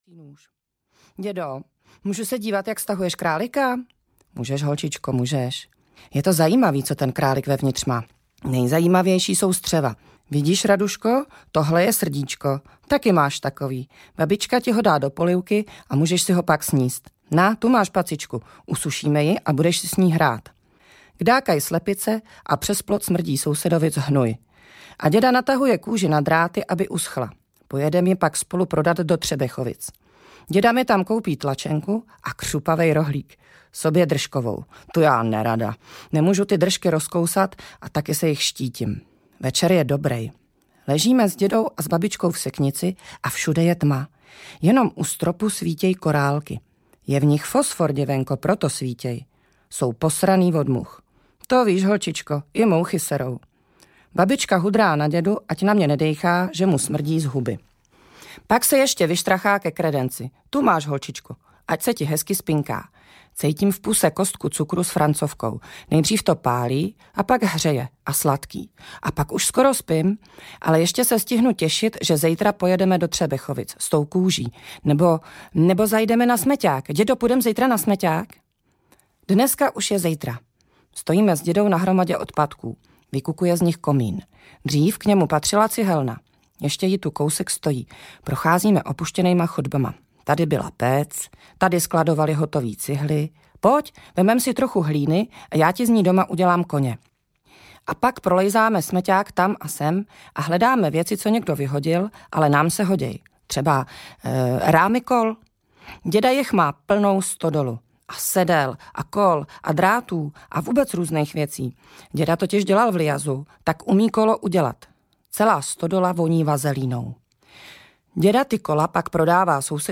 Čáp nejni kondor audiokniha
Ukázka z knihy
• InterpretRadůza
cap-nejni-kondor-audiokniha